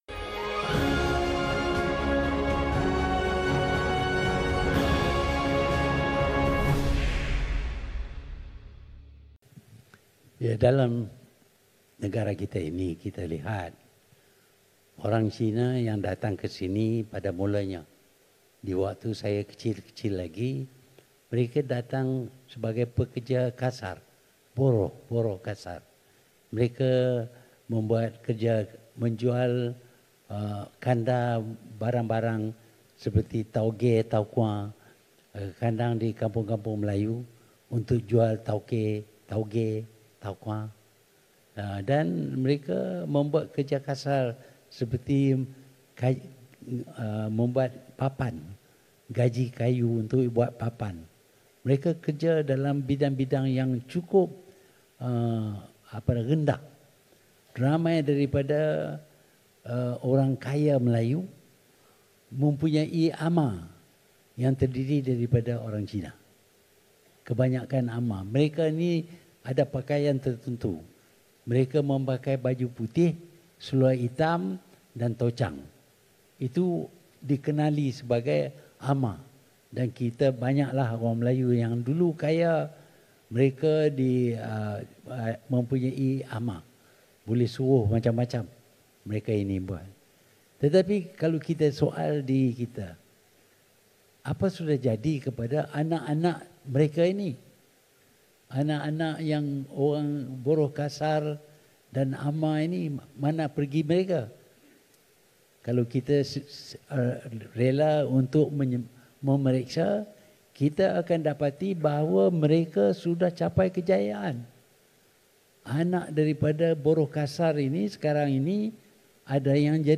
Perdana Menteri Tun Dr Mahathir Mohamad dalam satu jawapan pada sesi dialog Kongres Masa Depan Bumiputera dan Negara 2018 di Putrajaya mengulas mengenai jurang penguasaan ekonomi kaum Cina dan Bumiputera.